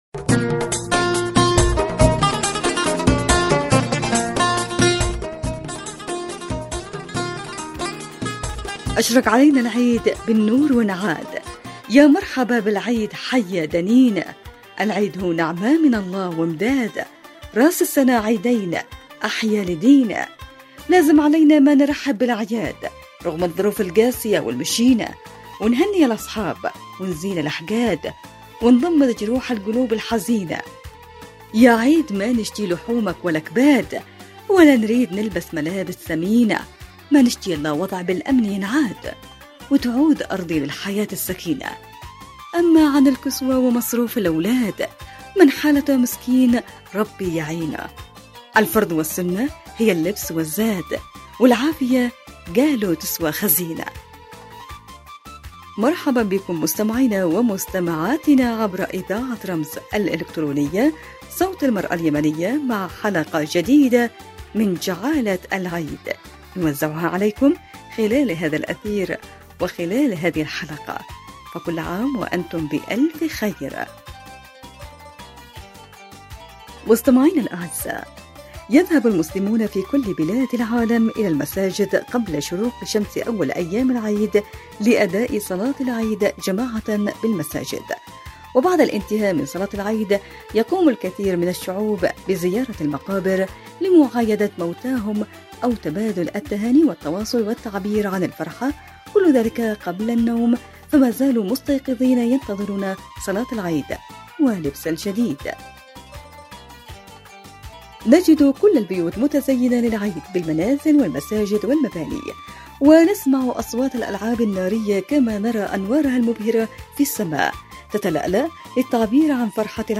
نشرة اخبارية تهتم بأخبار المرأة اليمنية والفعاليات والانشطة التي تشارك فيها وتخصها